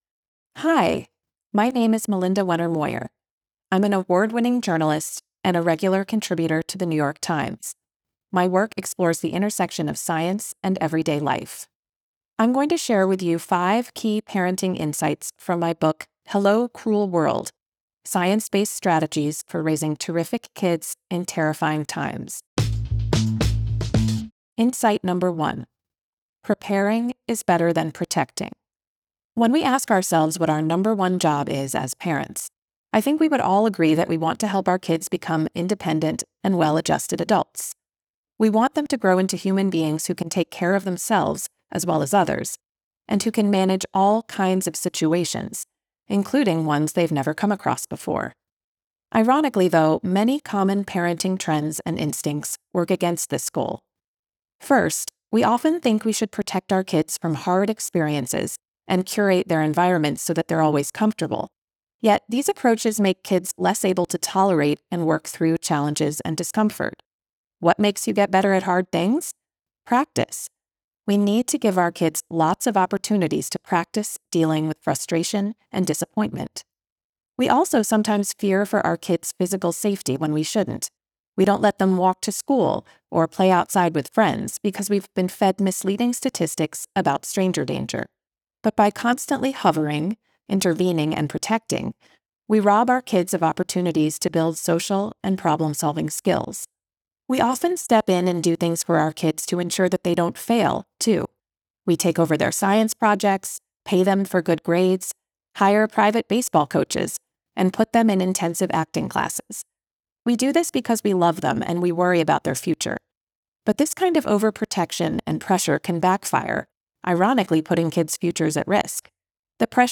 Book Bites Parenting Science